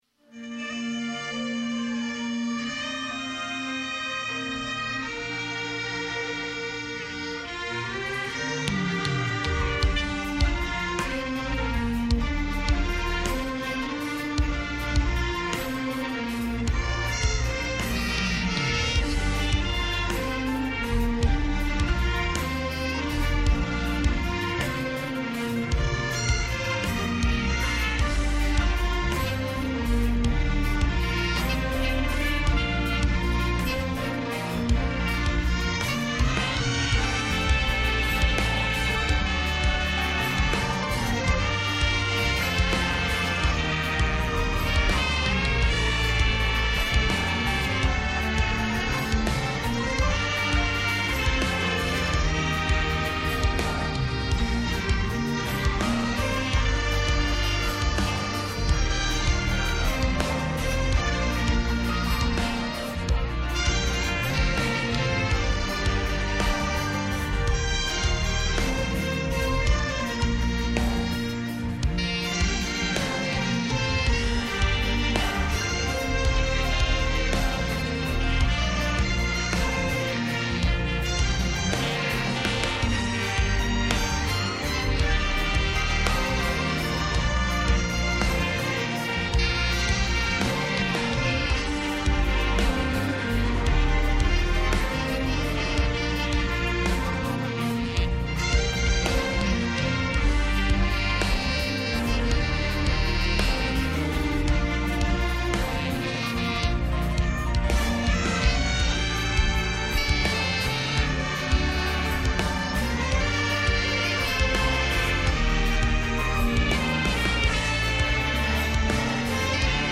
DOWNLOAD THE ORCHESTRA PLAY MP3